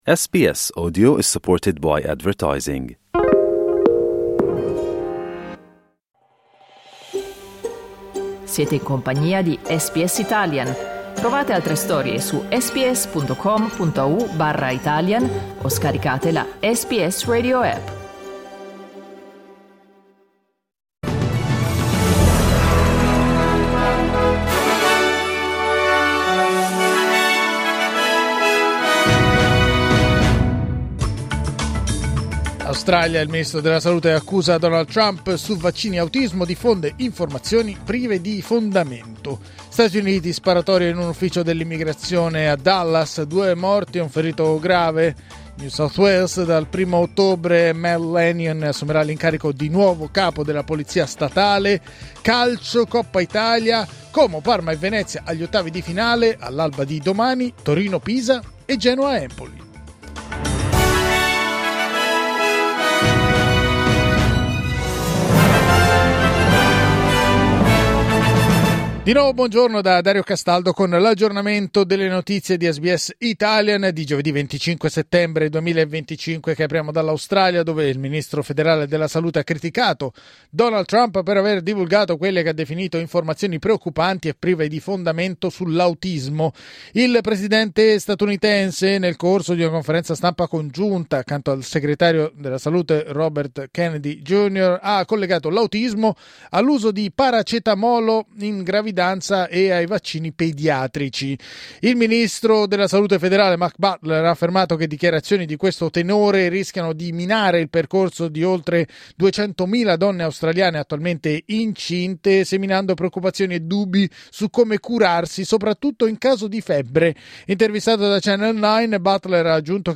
News flash giovedì 25 settembre 2025